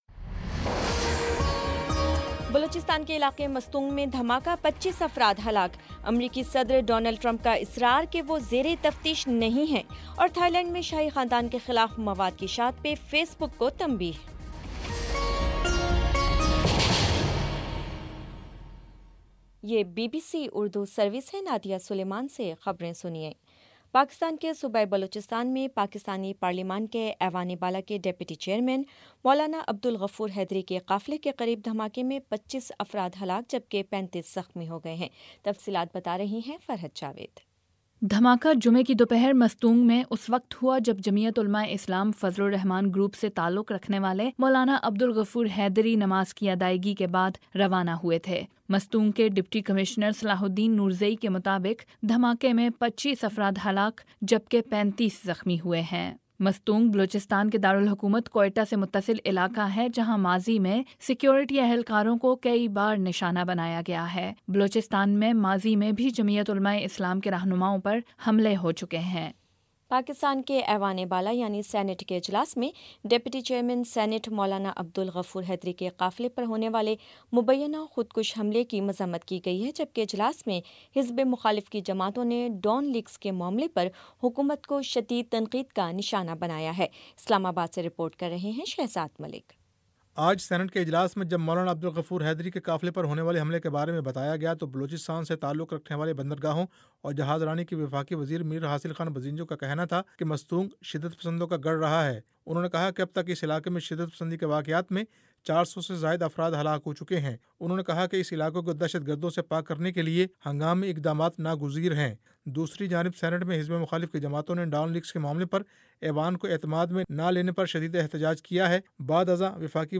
مئی 12 : شام چھ بجے کا نیوز بُلیٹن